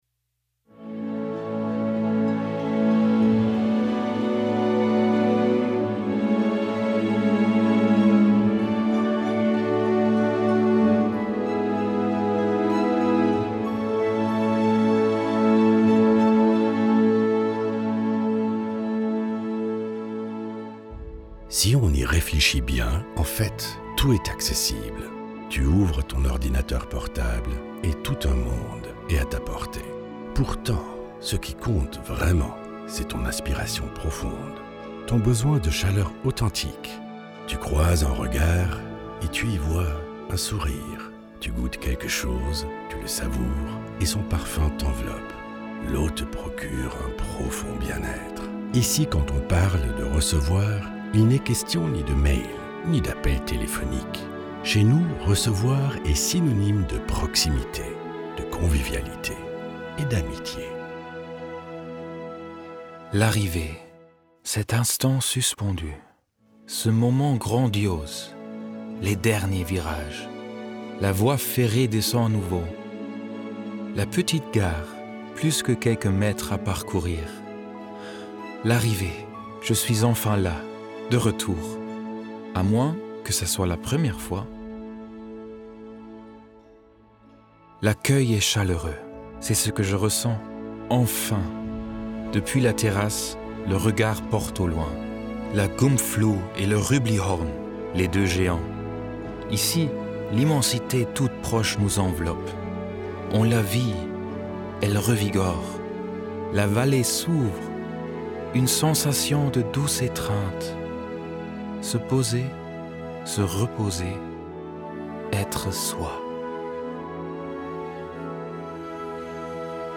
L'histoire de notre hôtel une expérience auditive émouvante
Pendant 17 minutes, vous serez transporté au cœur du Hornberg. D’une manière unique - raffinée, émouvante, profonde!